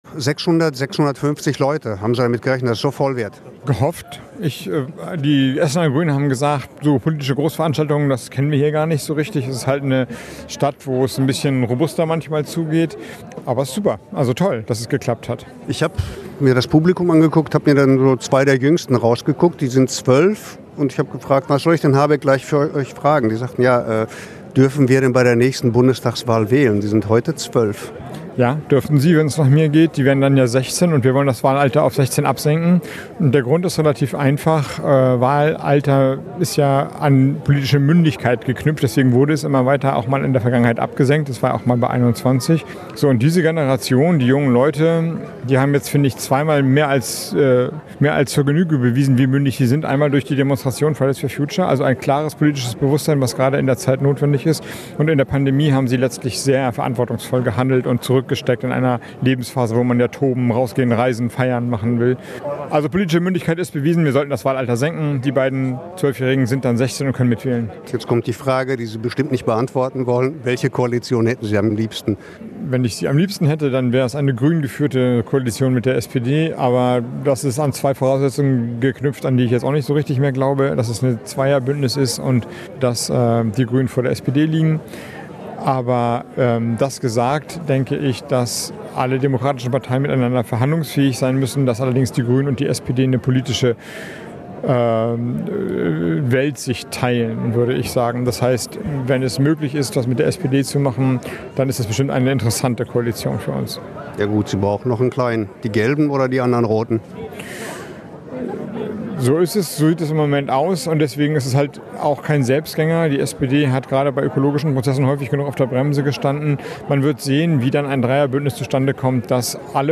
Grünen-Chef Robert Habeck hat in Essen Wahlkampf gemacht. Er sprach am Dienstag (21.09.21) über Umwelt, Wirtschaft und soziale Gerechtigkeit. Mehr als 600 Menschen waren zum Kennedyplatz gekommen, um Habeck zu hören.